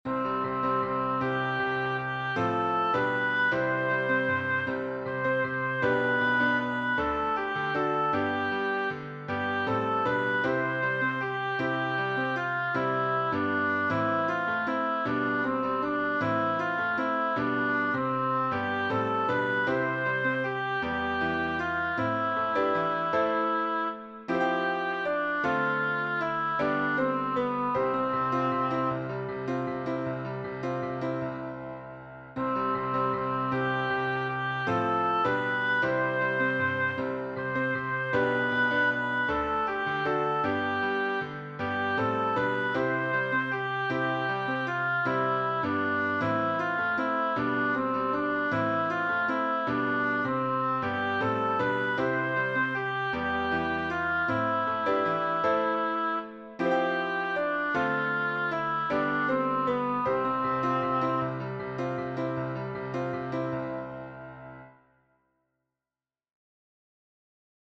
Traditional French carol